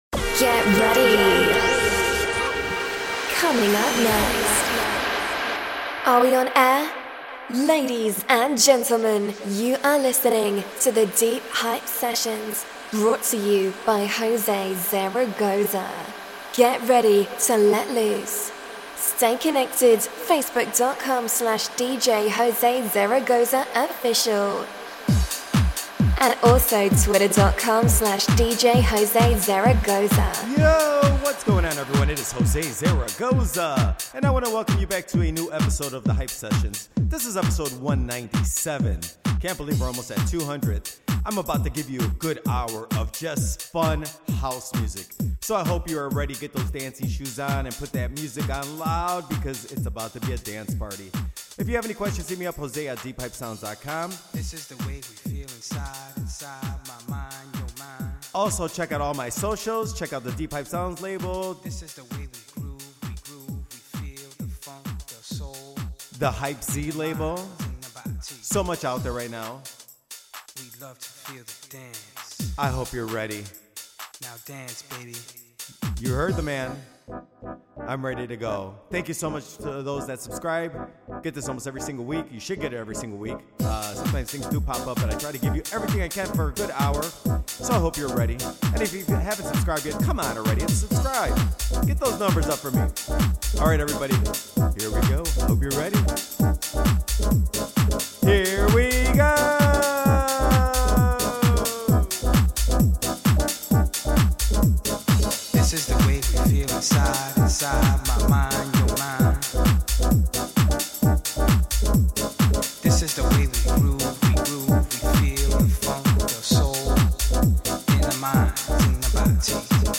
Full of fun house music!!!